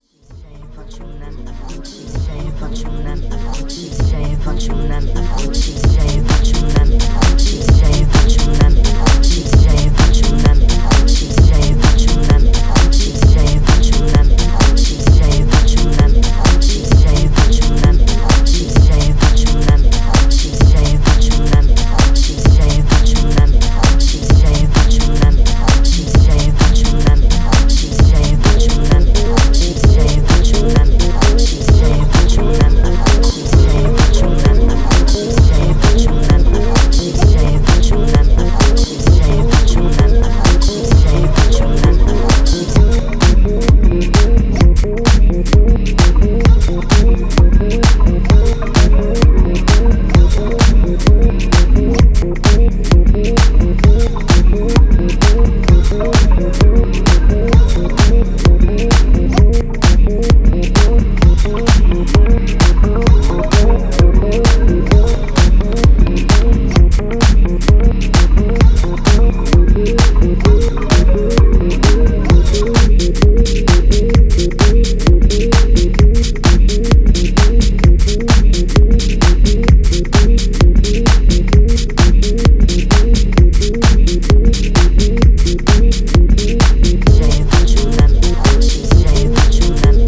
広がるようなボトムと液状のシンセリフ、ヴォイスサンプルでサイケデリックなレイヤーを構築する
深い時間で活躍してくれそうな、ミニマル/テック・ハウス路線の即戦力盤です！